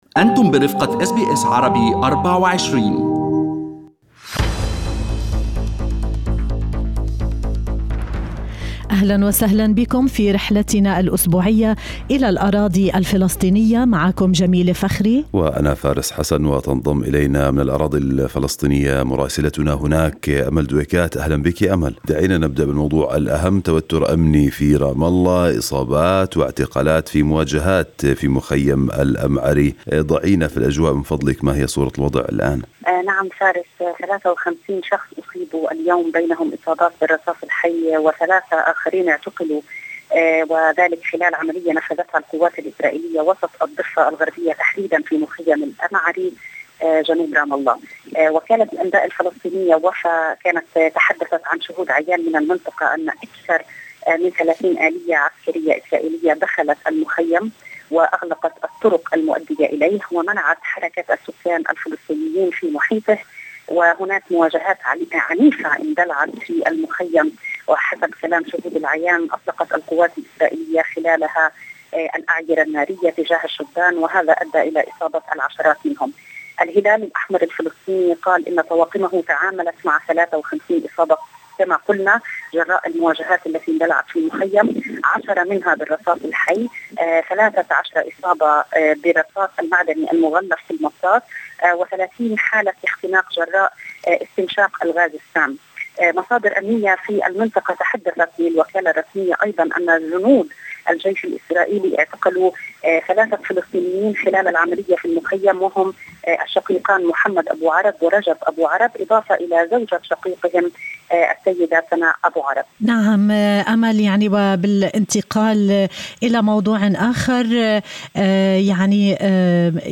يمكنكم الاستماع إلى تقرير مراسلتنا في رام الله بالضغط على التسجيل الصوتي أعلاه.